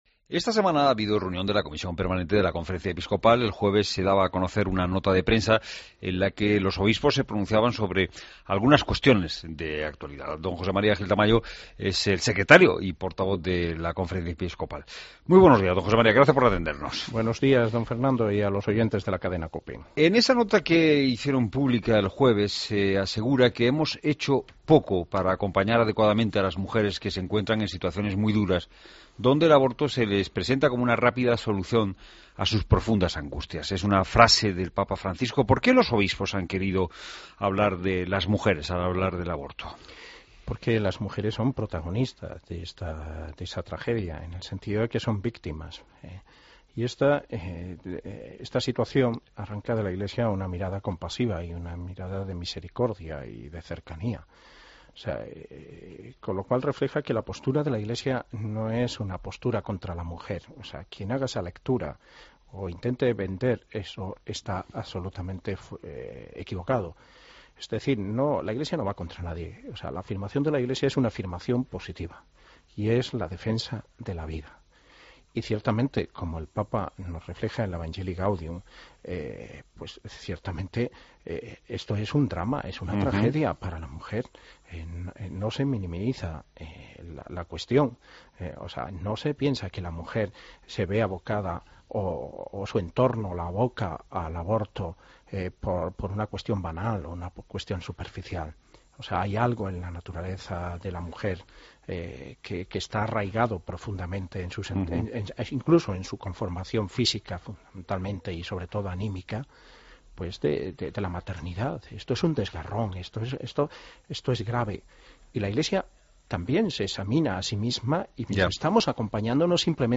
Entrevista a José María Gil Tamayo en La Mañana de la COPE